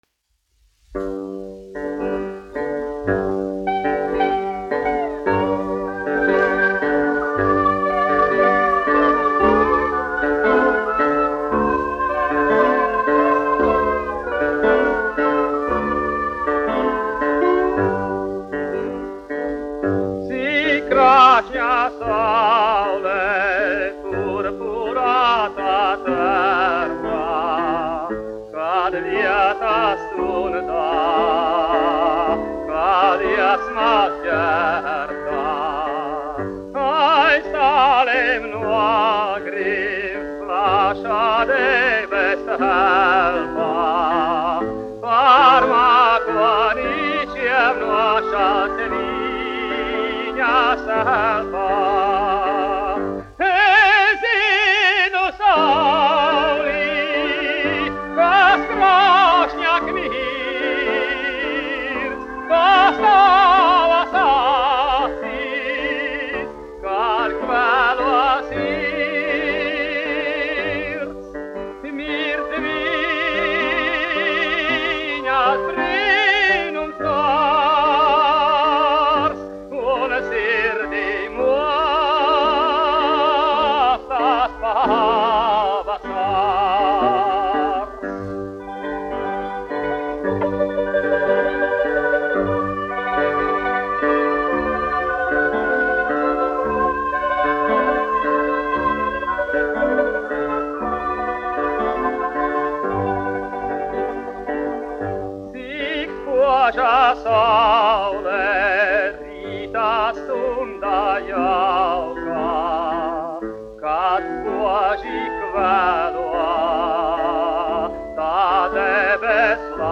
1 skpl. : analogs, 78 apgr/min, mono ; 25 cm
Dziesmas, neapoliešu
Populārā mūzika -- Itālija
Skaņuplate